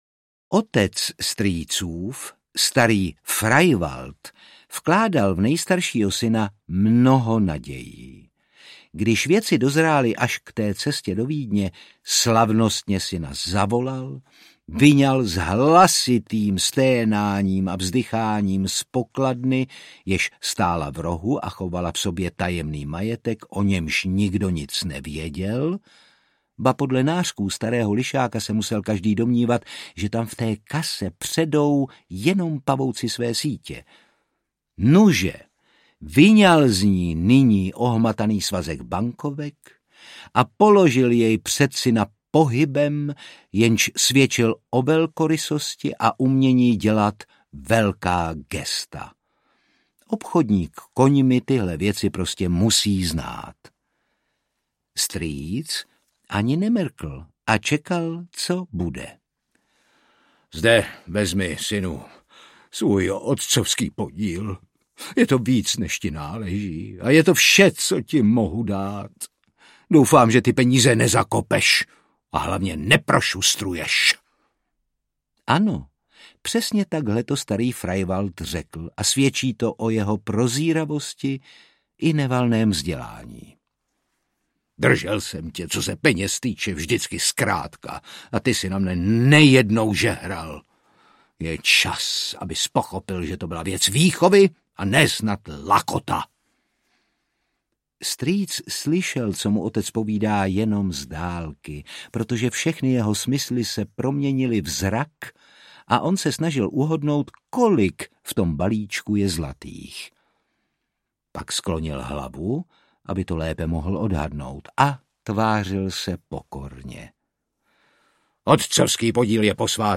Můj strýc Odysseus audiokniha
Ukázka z knihy
Čte Otakar Brousek.
Vyrobilo studio Soundguru.